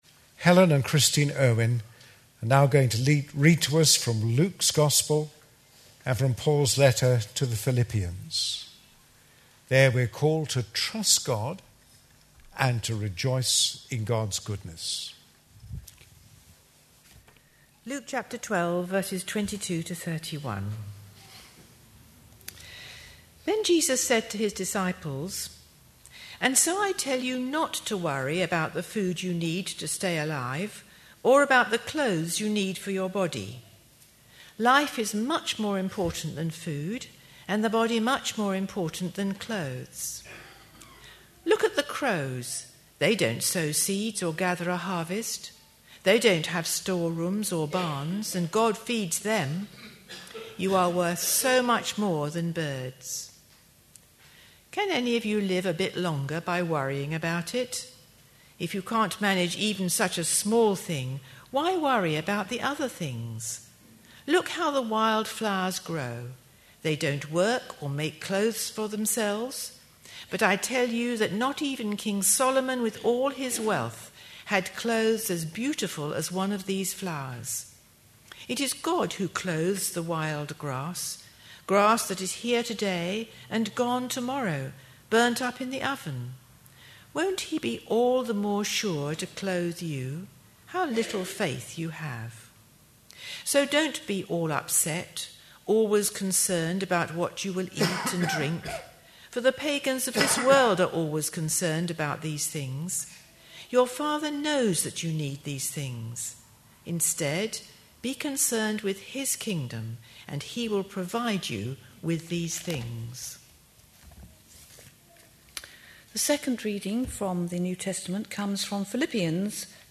A sermon preached on 2nd January, 2011.